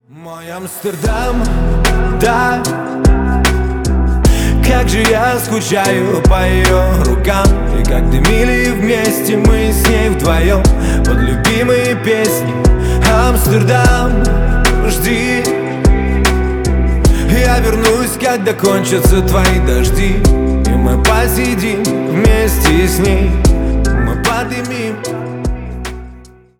Поп Музыка
спокойные # тихие